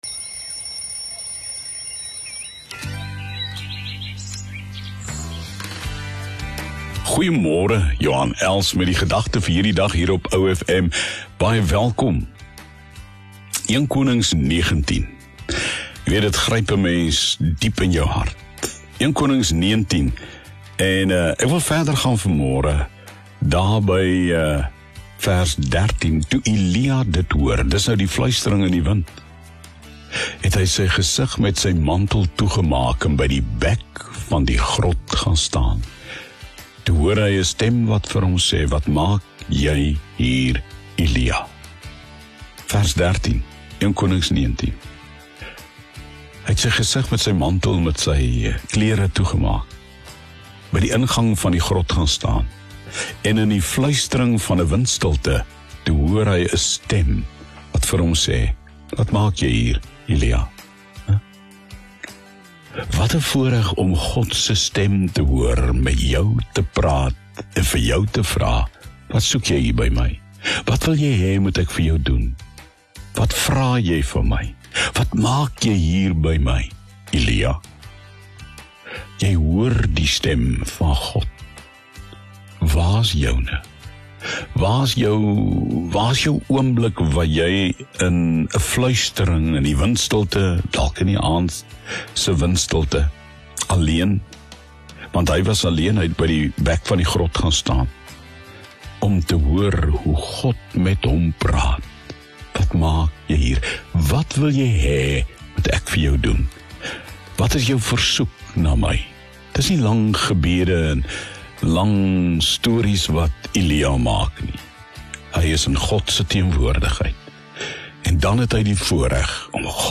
soos gehoor op OFM